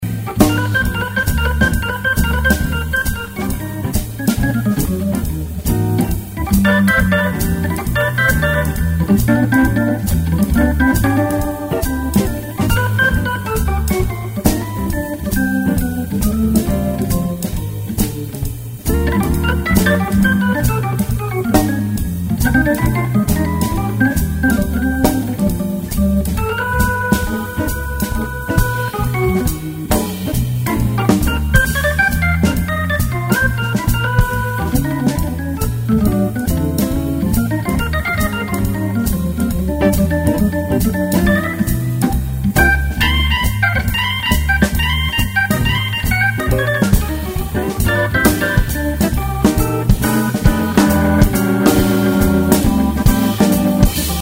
Qui est cet organiste ?
On l'entend maintenant sur B3.